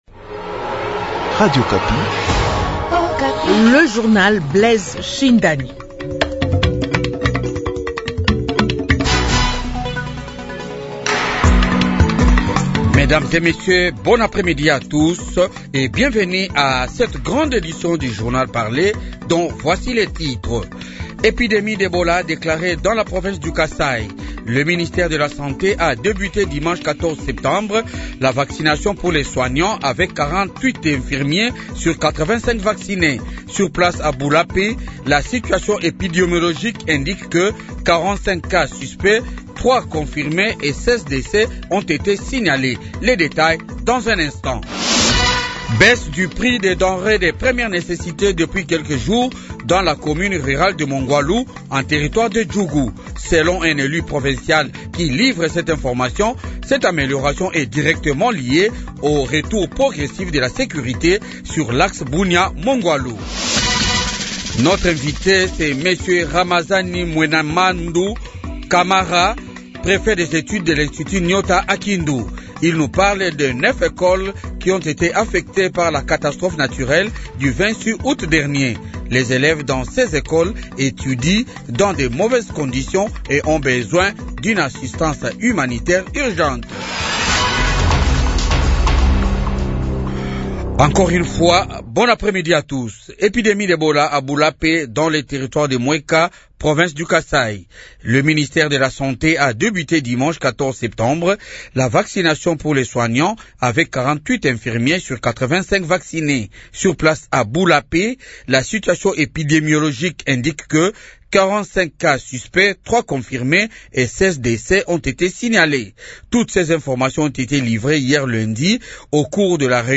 Journal de 15h